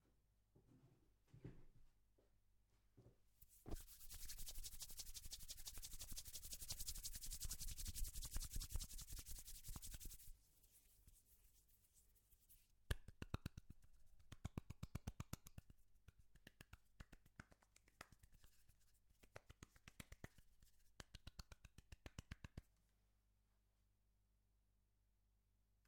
Test - condenser mic, through Mbox into Logic
Same levels set as for contact mic.